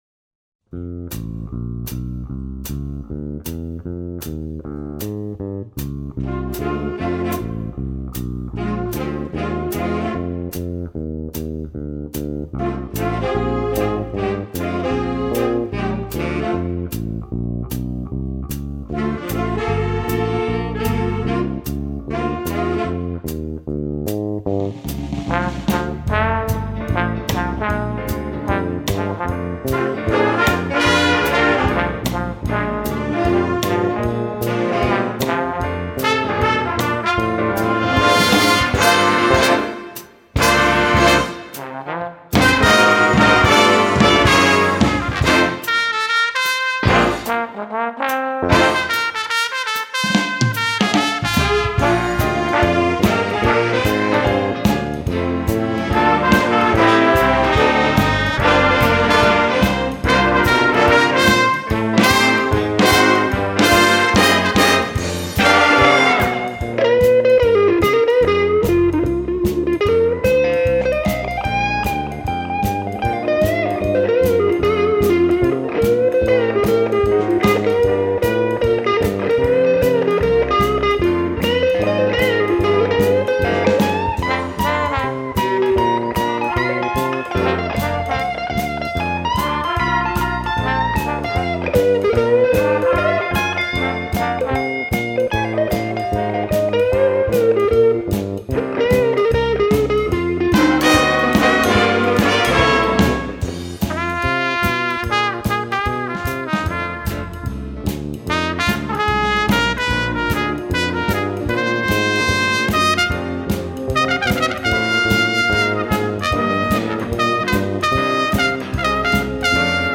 FSG-Bigband im SWR Tonstudio
Am 8.2.2018 durften wir bei einem Tonstudioworkshop der SWR-Bigband 5 Titel aufnehmen.